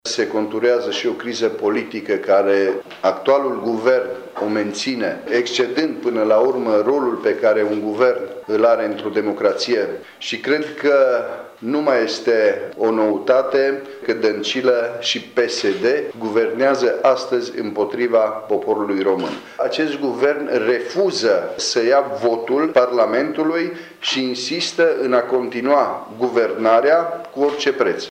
Senatorul liberal Cristian Chirteș, președintele filialei Mureș a PNL l-a acuzat în cadrul unei conferințe de presă pe actualul prim-ministru și pe partidul pe care îl conduce de guvernare împotriva poporului.